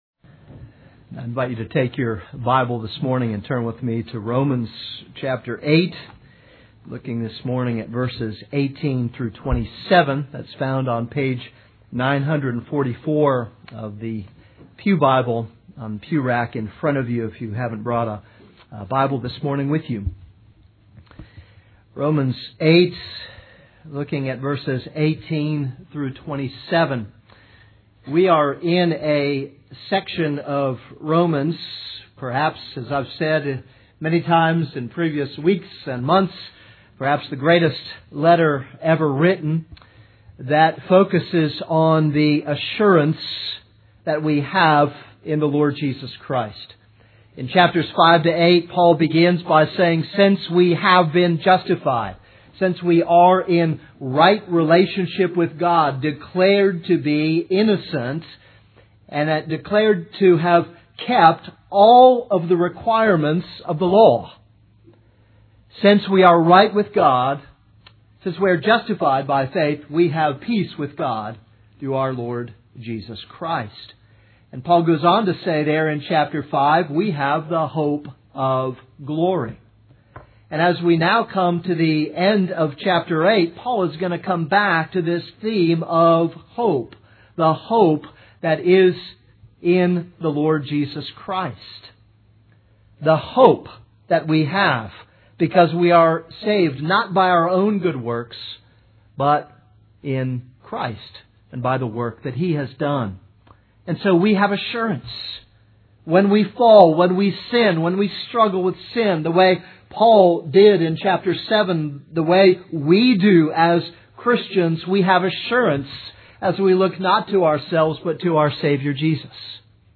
This is a sermon on Romans 8:18-27.